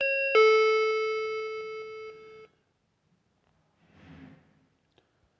klingel_aufnahme_handy.wav